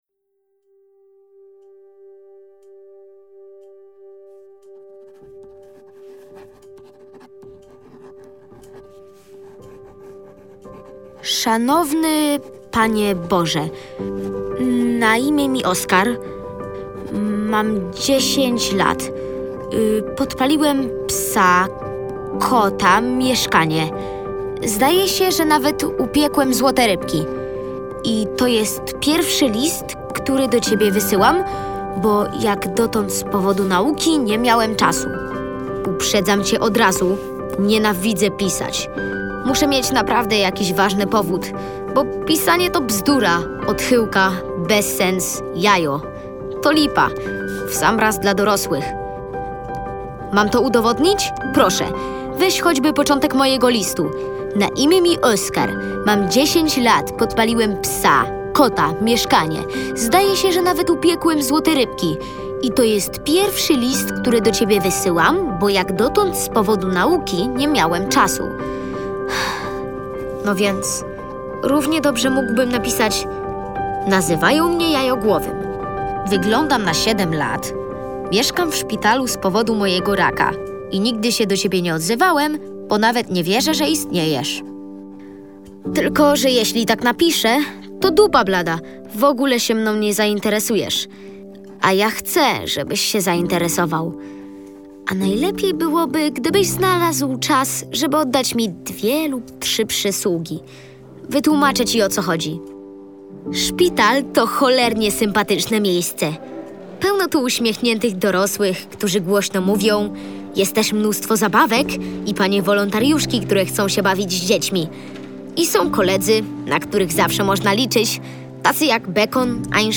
Oskar i pani Róża - Eric-Emmanuel Schmitt - audiobook + książka